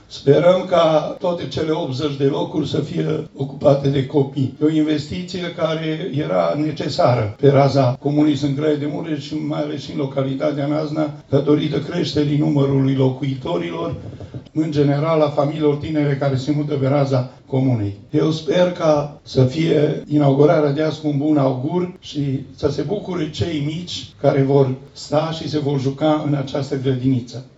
Primarul comunei Sâncraiu de Mureș, Remus Sângeorzan, crede că aceasta va fi un punct de atracție pentru cei care vor să se stabilească în localitate: